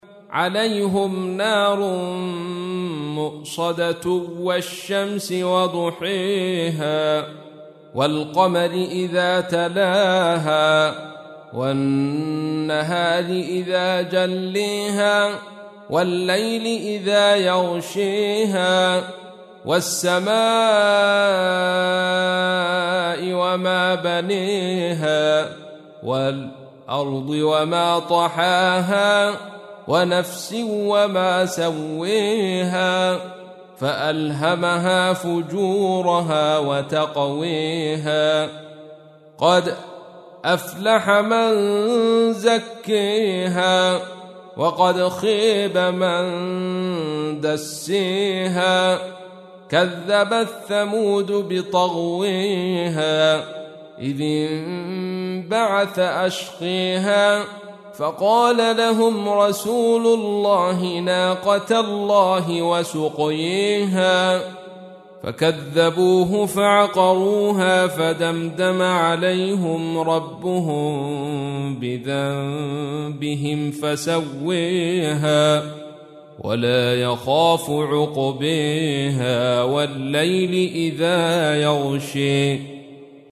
تحميل : 91. سورة الشمس / القارئ عبد الرشيد صوفي / القرآن الكريم / موقع يا حسين